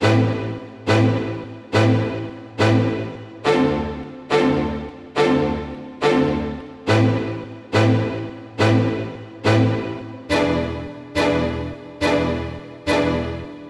小提琴管弦乐Reeverb
描述：小提琴管弦乐的再创作
Tag: 140 bpm Orchestral Loops Violin Loops 2.31 MB wav Key : Unknown